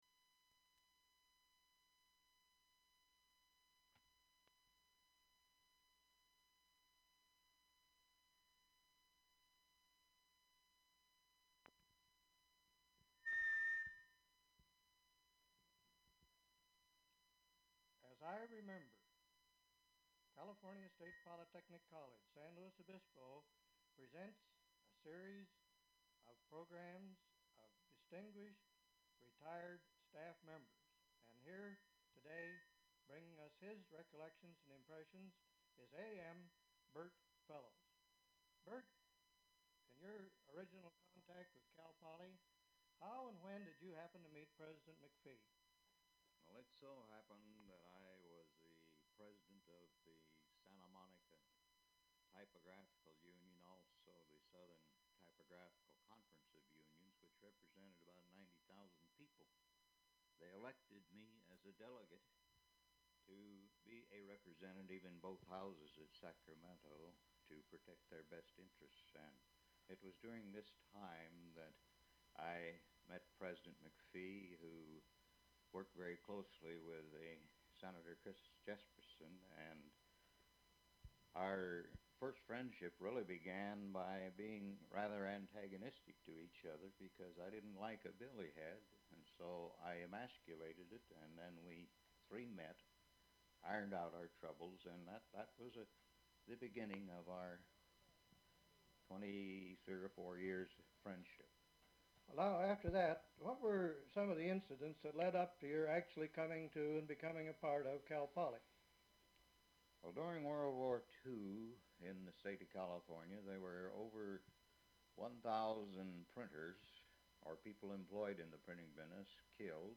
Form of original Open reel audiotape